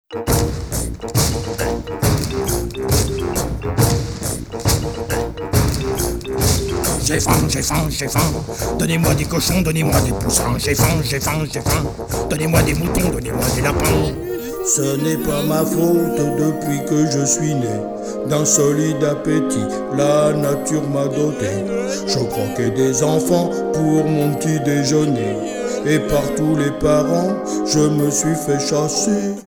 En avant première, un extrait des chansons de Bartacus, Lili et Zélectron !